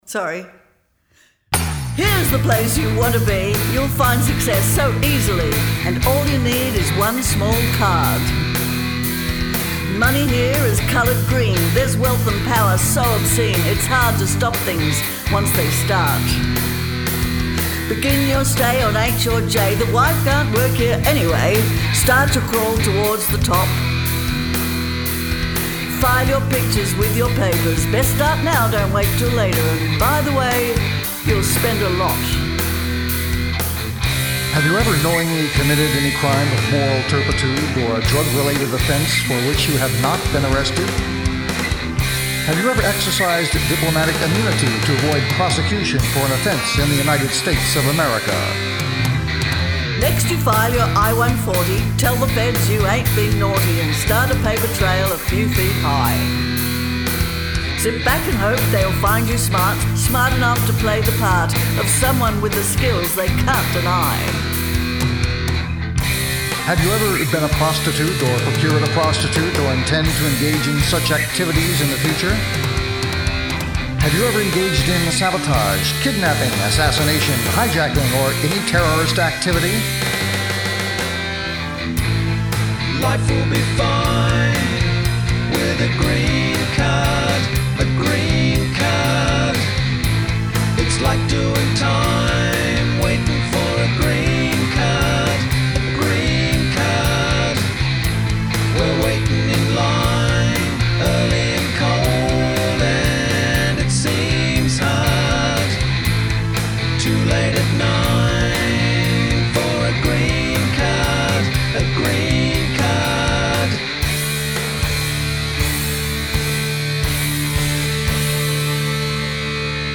Vocals, Guitars, Keyboards & Drums.
Bass.